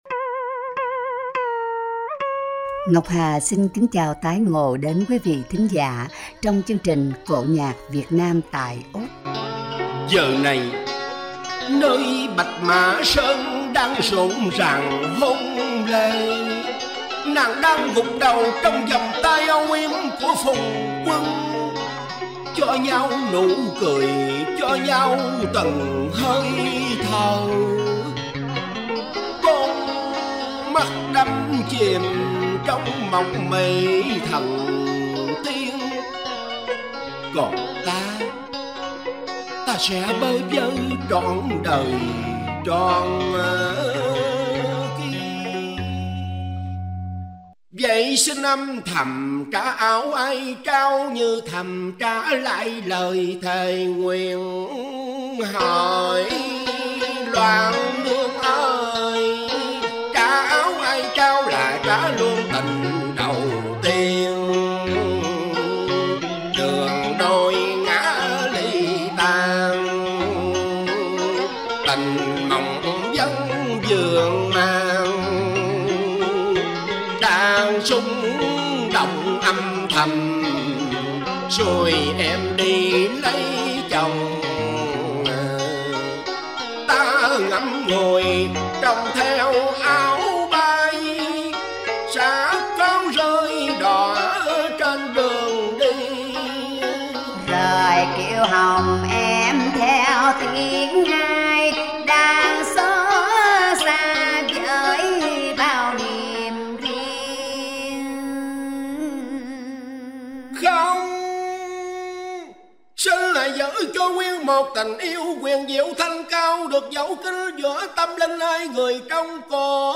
Tiếng hát cải lương
Những âm điệu ngũ cung của bài vọng cổ